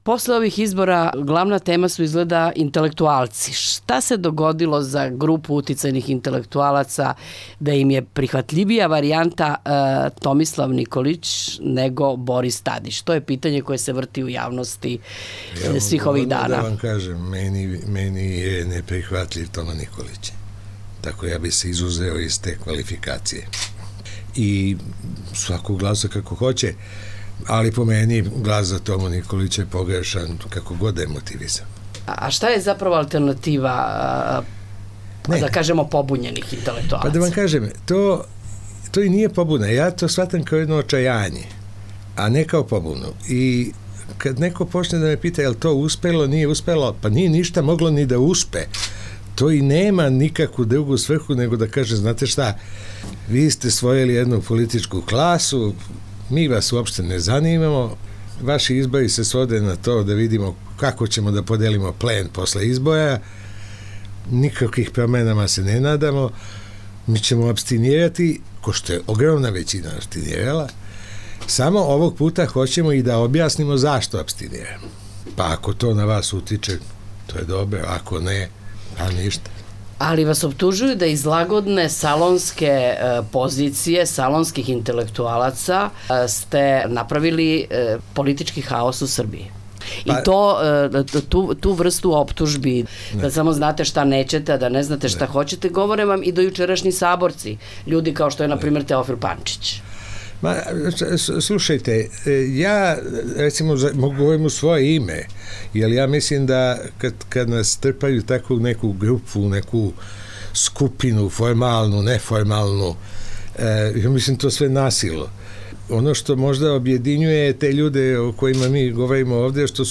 Intervju: Srđa Popović